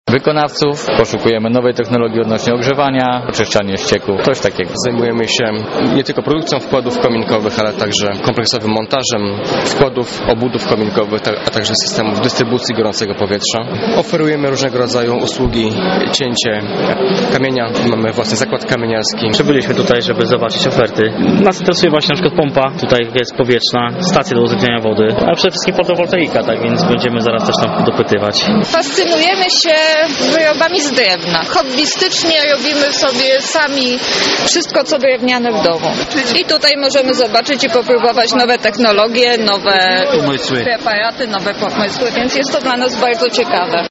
Na miejscu wydarzenia była nasza reporterka: